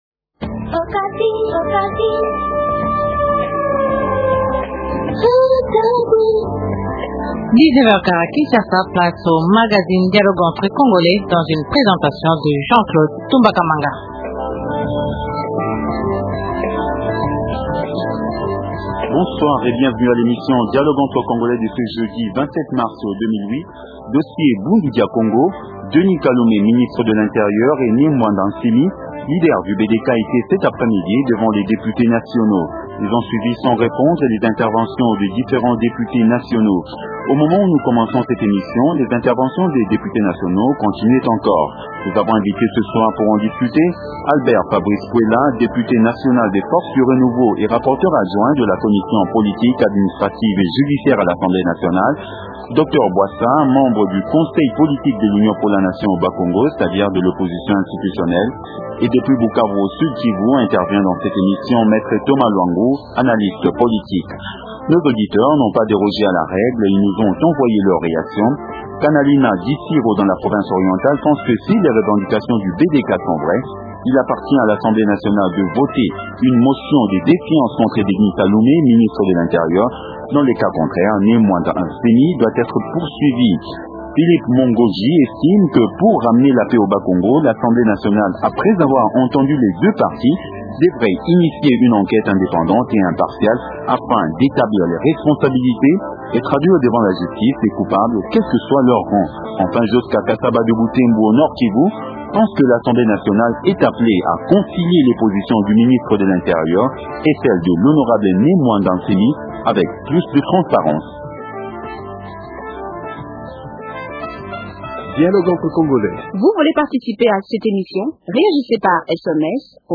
Denis Kalume, Ministre de l’intérieur et Ne Muanda Nsemi, leader du BDK devant les députés nationaux